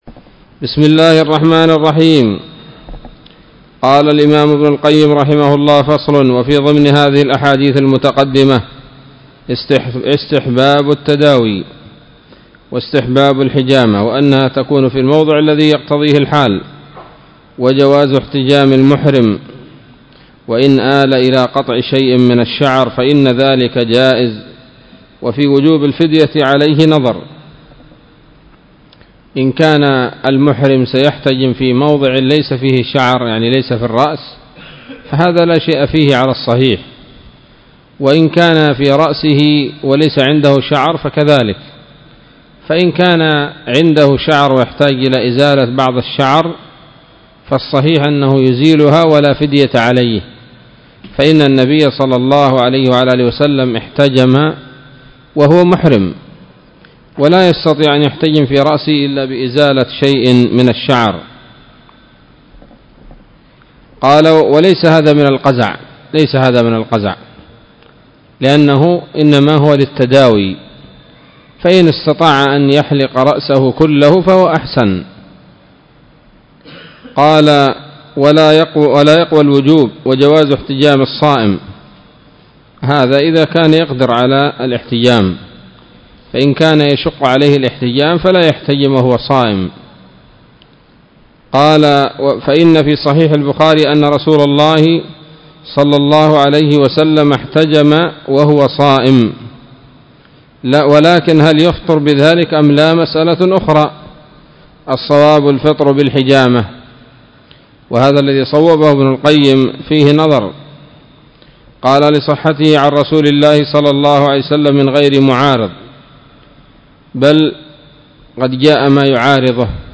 الدرس السابع عشر من كتاب الطب النبوي لابن القيم